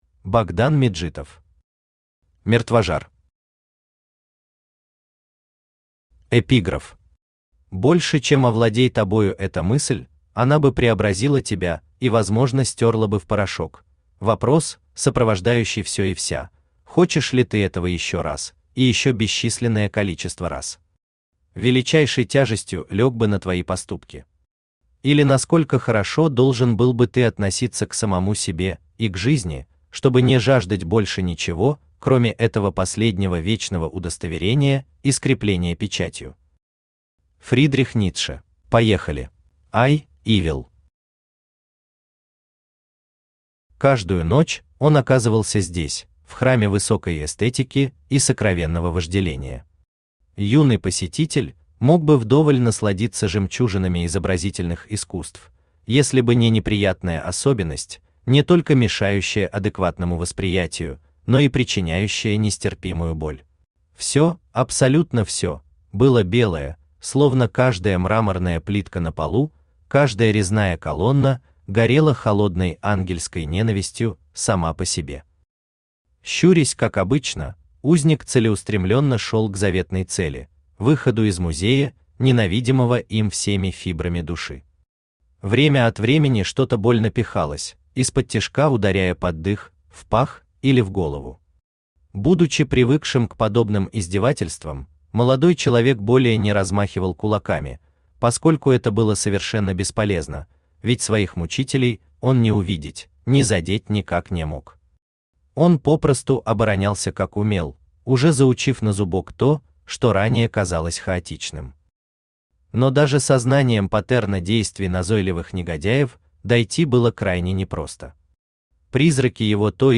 Аудиокнига Мертвожар | Библиотека аудиокниг
Aудиокнига Мертвожар Автор Богдан Меджитов Читает аудиокнигу Авточтец ЛитРес.